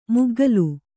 ♪ muggalu